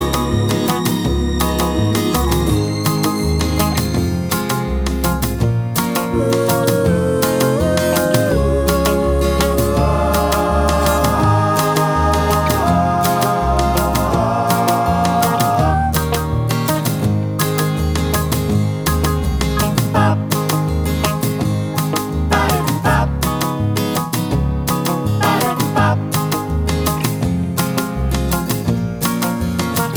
No Saxophone Pop (1960s) 2:15 Buy £1.50